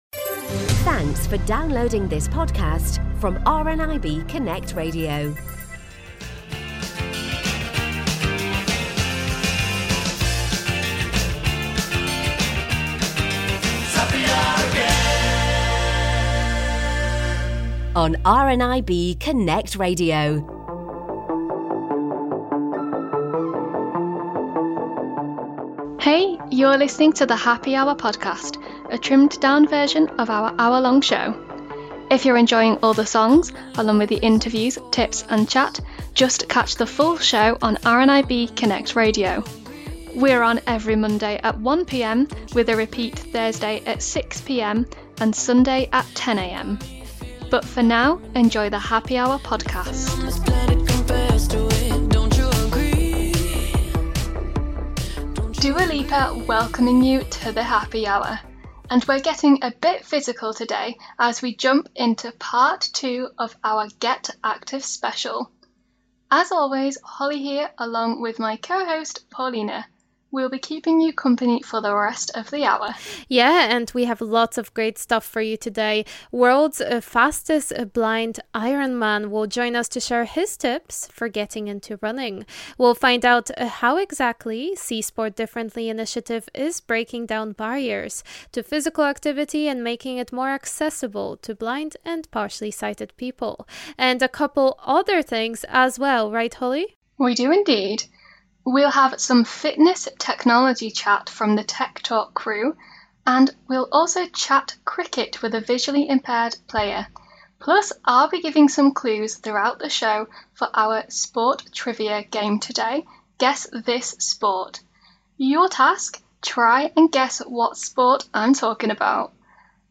The Happy Hour Podcast is our trimmed down version of this hour-long show, so if you'd like to listen to the full show with all the amazing songs featured, catch a new episode of the Happy Hour on RNIB Connect Radio Mondays at 1 PM, with a repeat Thursday at 6 PM and Sunday at 10 am.